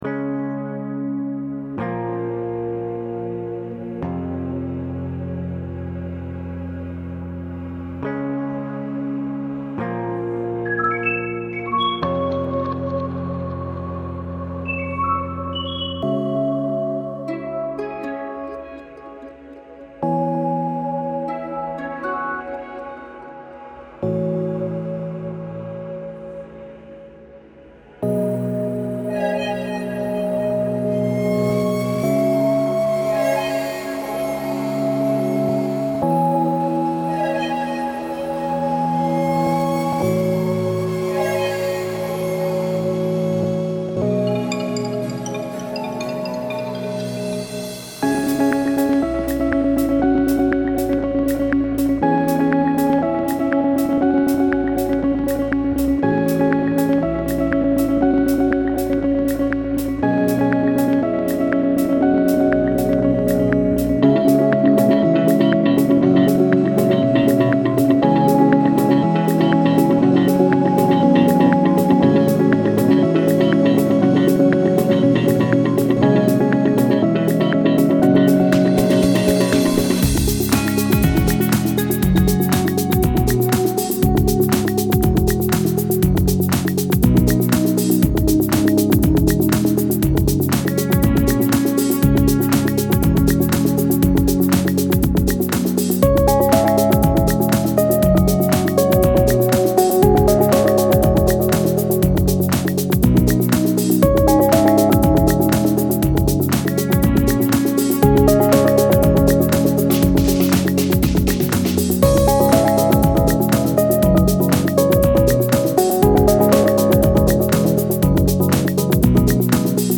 Немного театральной музыки